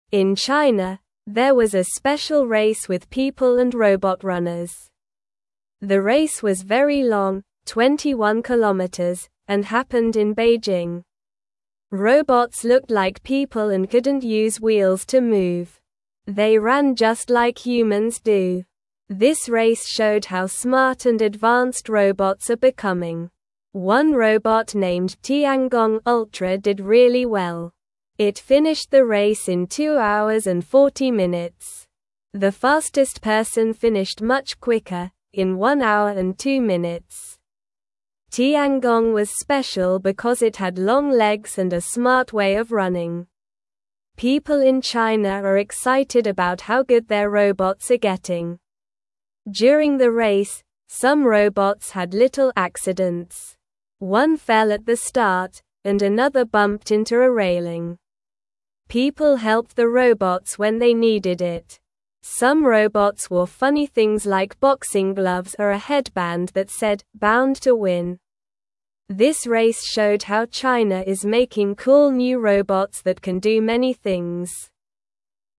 Slow
English-Newsroom-Beginner-SLOW-Reading-Robots-and-People-Race-Together-in-China.mp3